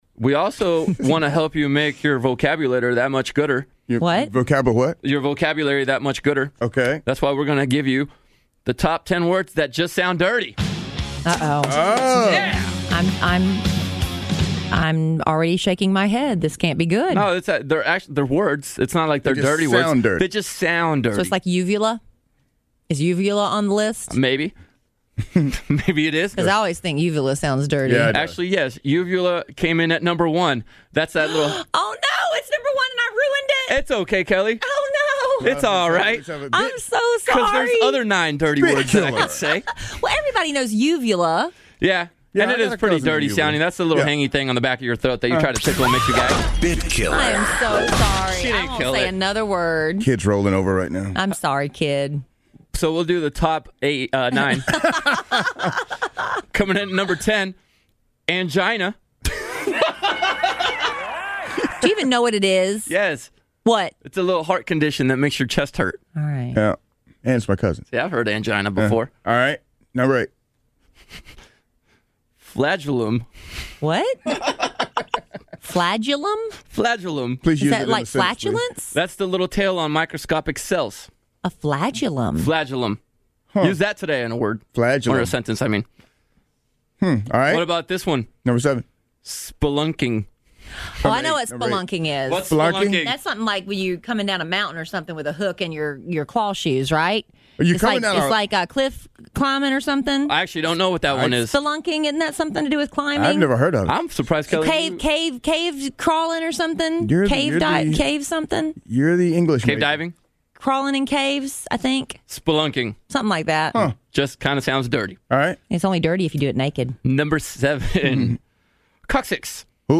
reads a list of the top ten words that are clean, but sound dirty.